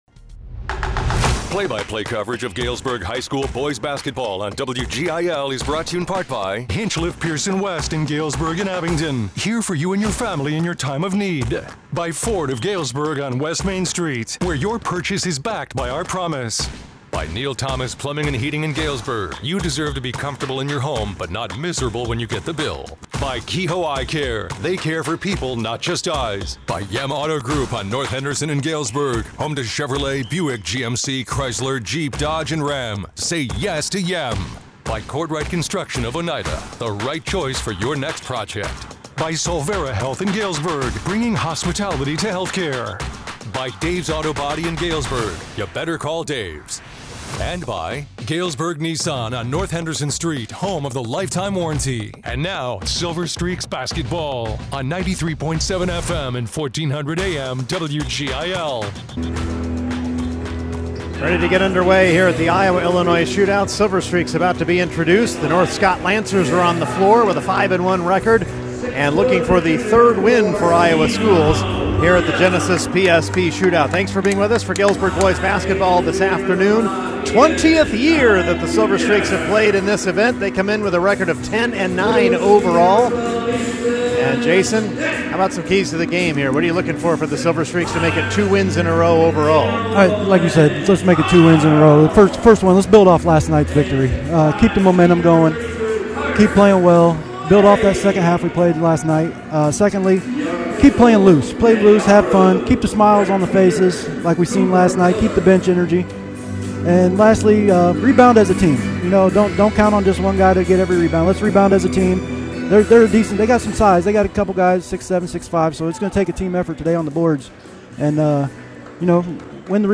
Galesburg Silver Streaks Boys Basketball vs. North Scott Lancers